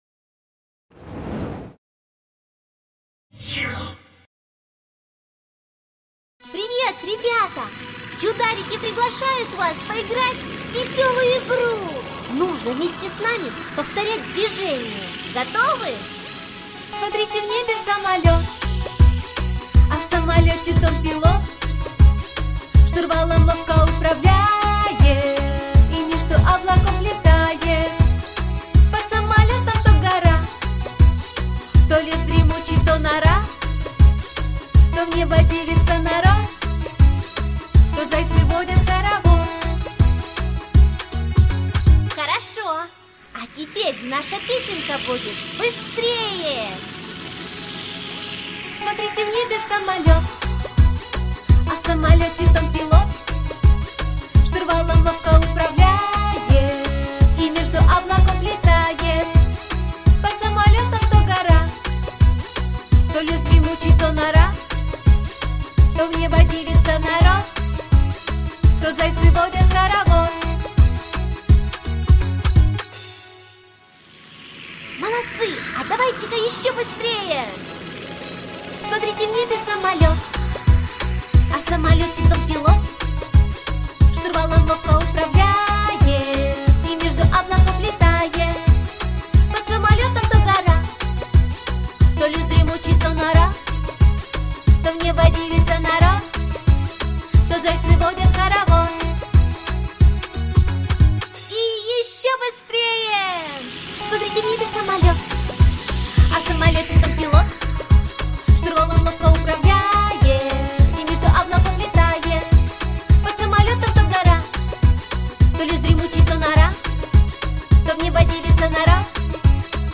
Игра самолет - песенка с движениями - слушать онлайн